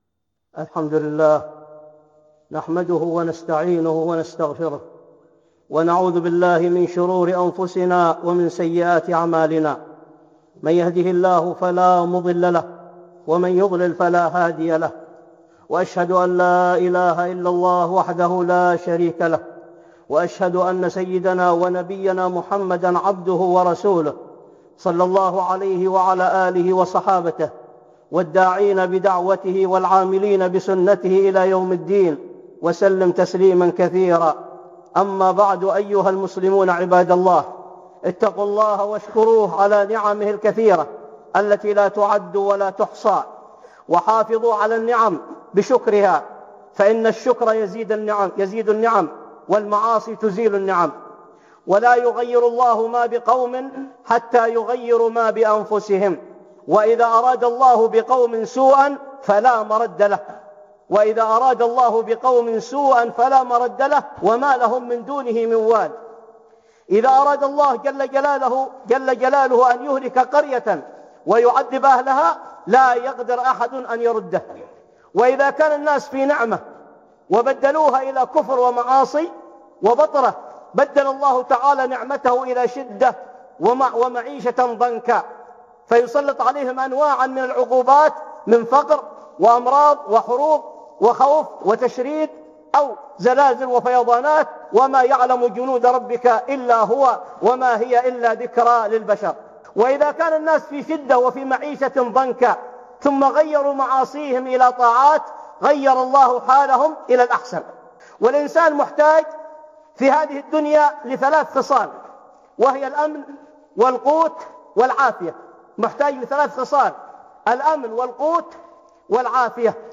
(خطبة جمعة) بعنوان